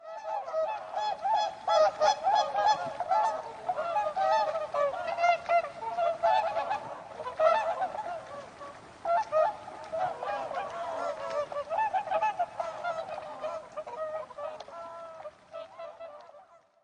大天鹅叫声 单调粗哑似喇叭声